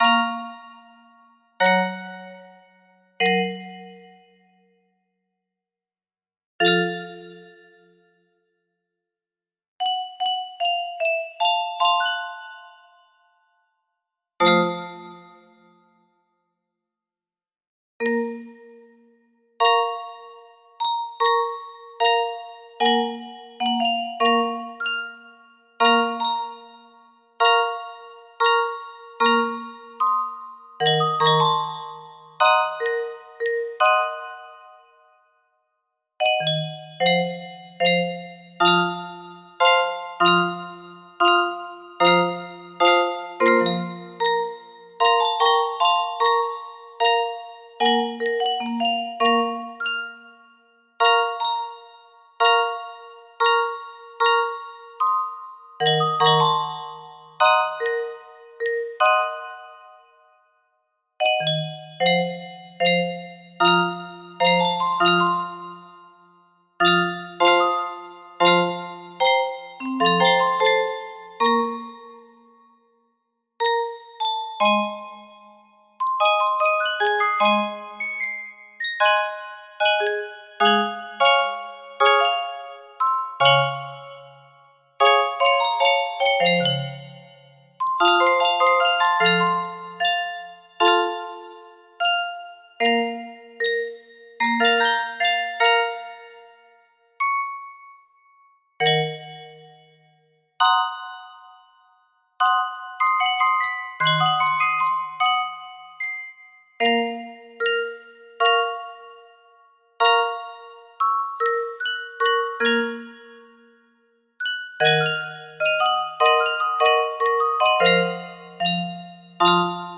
Full range 60
Hungarian Folk Style Music also
Known as Gypsy Style Music.